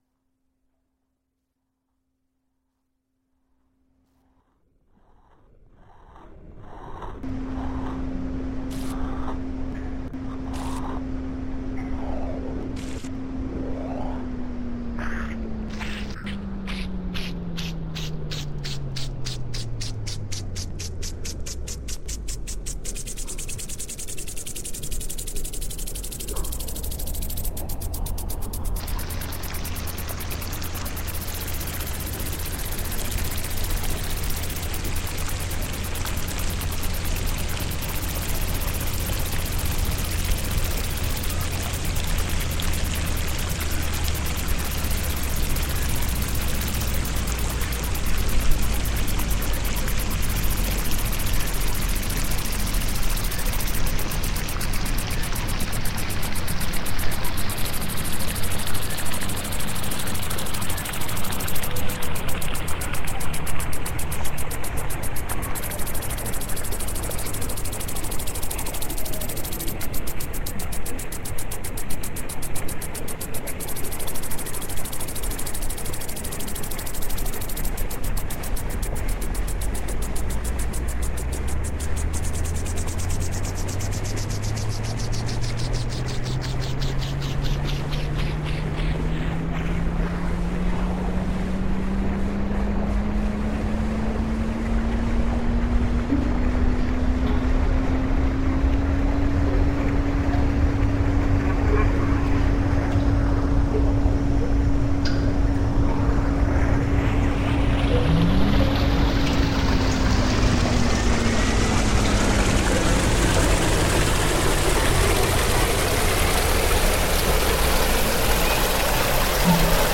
created a patch in maxmsp that plays the tracks and randomly choses the mix the speed and volume of each track.
reimagines the sounds of water in Southwark, London.
Part of our Sound Waves project for World Listening Day 2015.